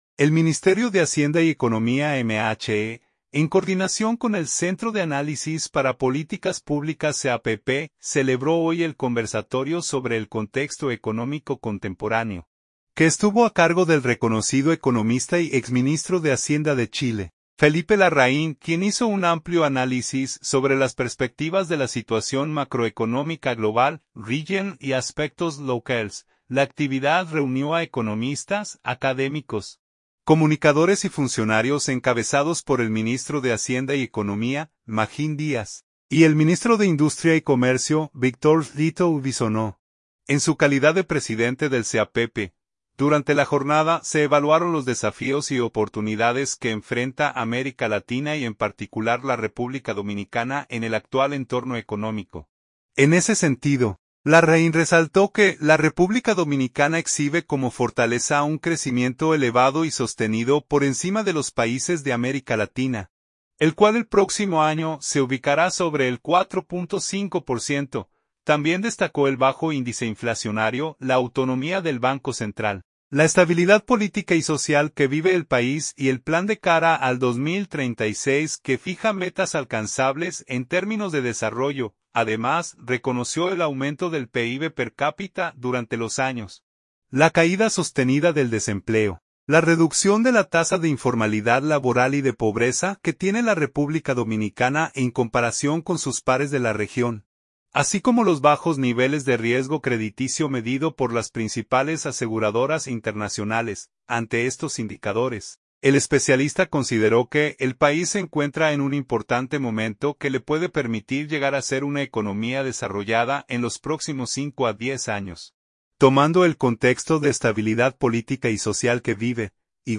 El Ministerio de Hacienda y Economía (MHE), en coordinación con el Centro de Análisis para Políticas Públicas (CAPP), celebró hoy el “Conversatorio sobre el contexto económico contemporáneo”, que estuvo a cargo del reconocido economista y exministro de Hacienda de Chile, Felipe Larraín, quien hizo un amplio análisis sobre las perspectivas de la situación macroeconómica global, regional y aspectos locales.
El conversatorio, realizado en la sala Jesús María Troncoso del Banco Central de la República Dominicana (BCRD), se convirtió en un espacio para el análisis y la actualización técnica, reafirmando el compromiso institucional del MHE y del Centro de Análisis para Políticas Públicas con la formación continua y la promoción de políticas económicas alineadas con estándares internacionales de transparencia y sostenibilidad.